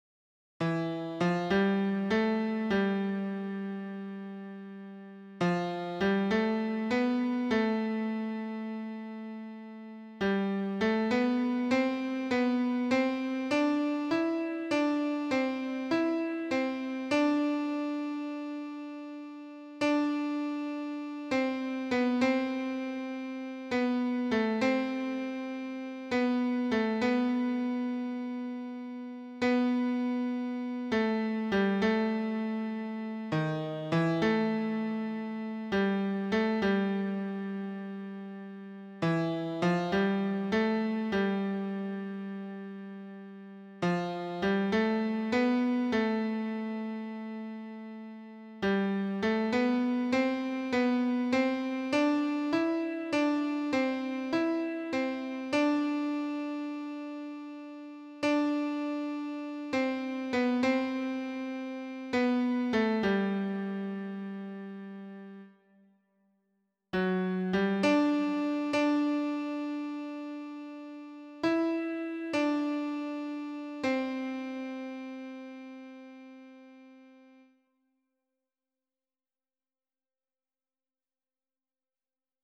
esse_seu_olhar_-_tenor[50301].mp3